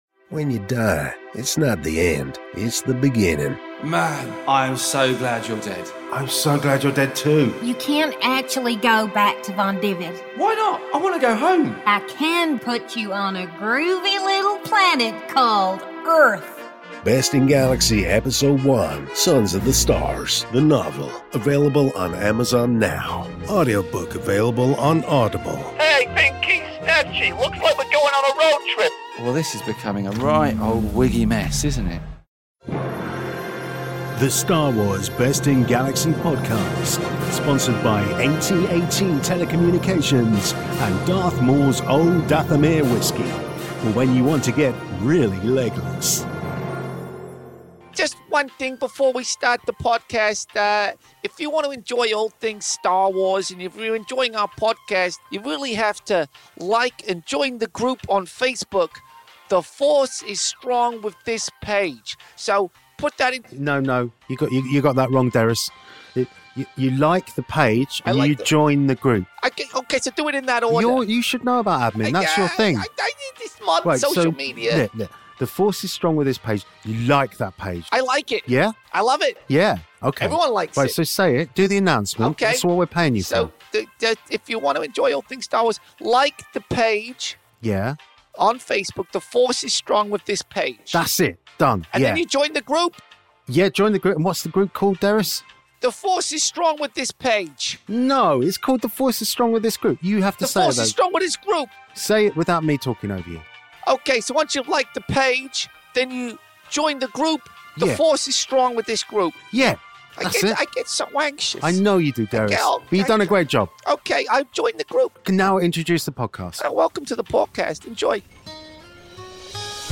S02 Ep05 of the only improvised unofficial Star Wars based parody sitcom podcast in the galaxy! Having successfully rescued TK-127 from the Galaxy Killer Galaxy Base and outwitting General Beers and the rest of the First Order, the lads attempt to defrost their friend from his carbonite tomb and learn a deadly secret about an imminent attack that could destroy everything - including Star Wars.